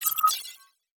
Radar Sensor Notification.wav